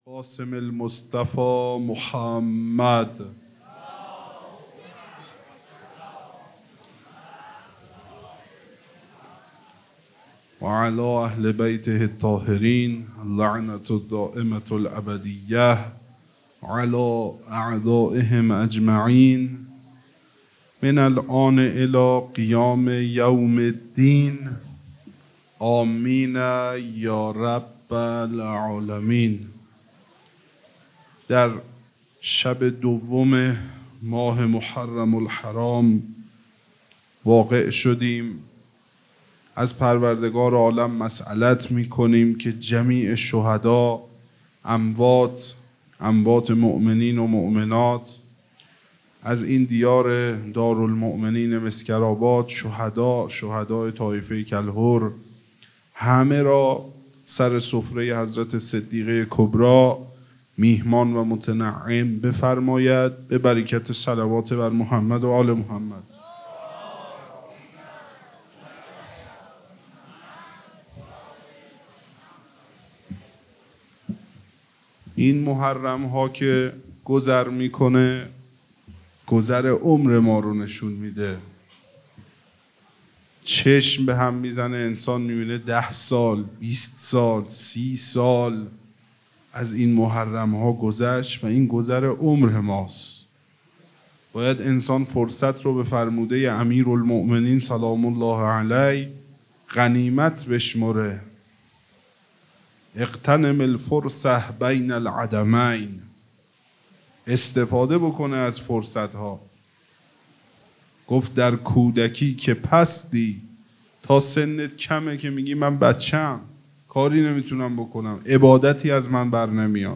خیمه گاه - هیئت محبان الحسین علیه السلام مسگرآباد - سخنرانی